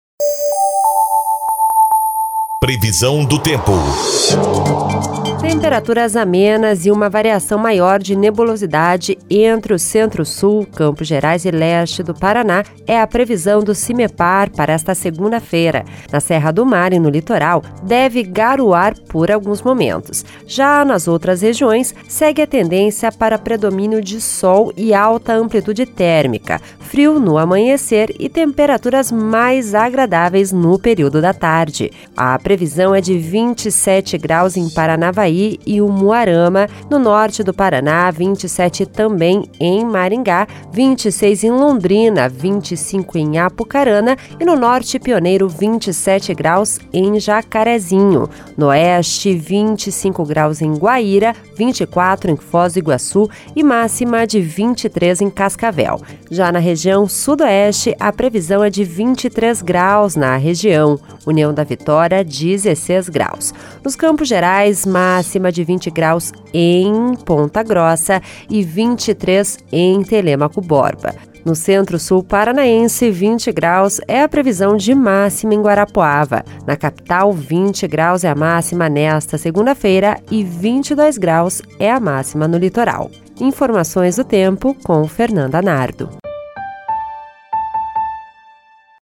Previsão do Tempo (09/05)